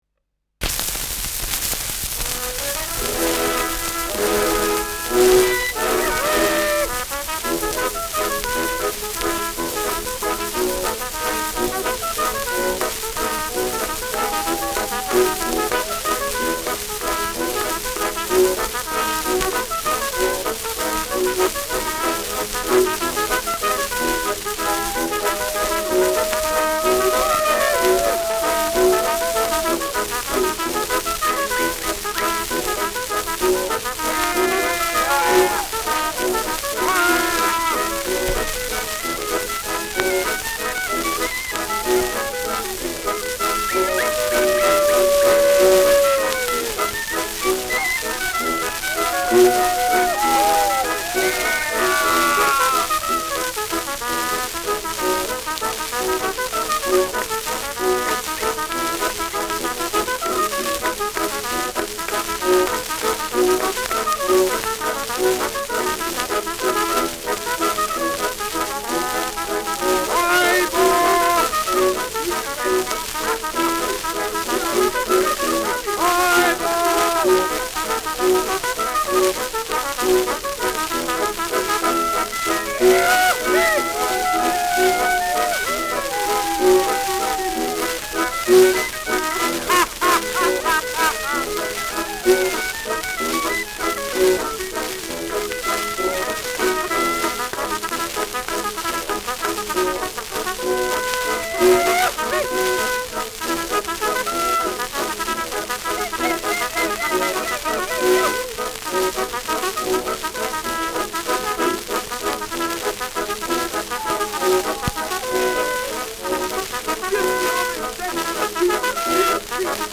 Schellackplatte
Juchzer, Klopfgeräusche, Pfiffe, Zwischenrufe, Lachen